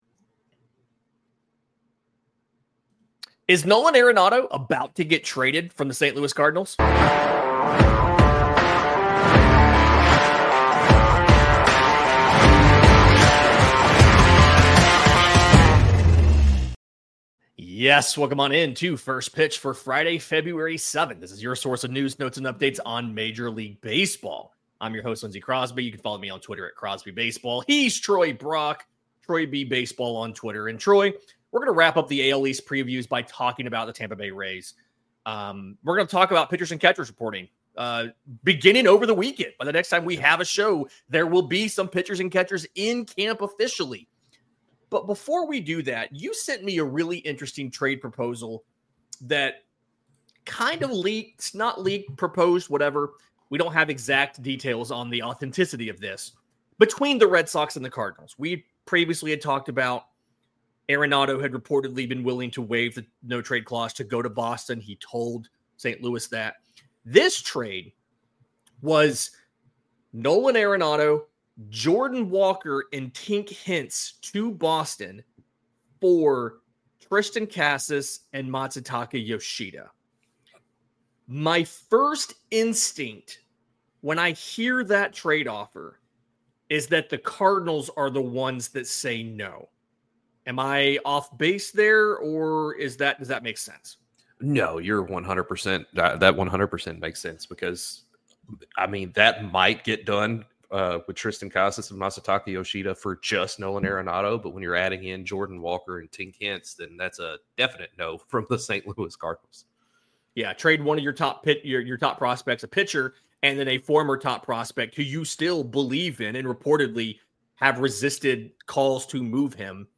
On today's show, the boys break down a rumored trade offer between the Cardinals and Red Sox for 3B Nolan Arenado before wrapping up their AL East previews with the Tampa Bay Rays: Do their young players come into their own this year?